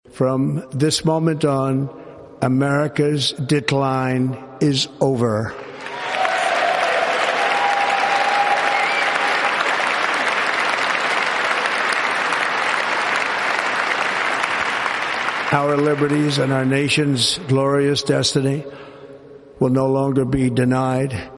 Donald Trump has been inaugurated as the 47th President of the United States in a historic ceremony held indoors at the Capitol Rotunda due to dangerously low temperatures.